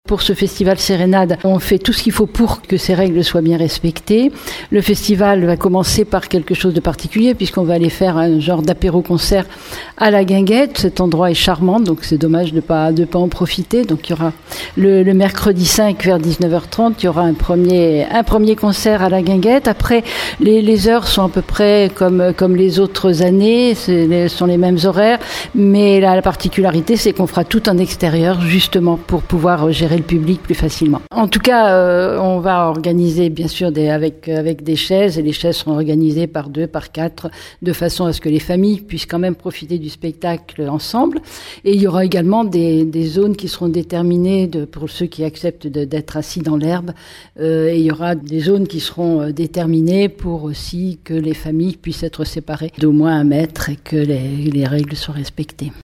Cette année, mesures de sécurité sanitaire obligent, tous les concerts se dérouleront en plein air, comme le souligne la maire de la ville Catherine Desprez :